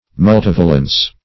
Multivalence \Mul*tiv"a*lence\, n. (Chem.)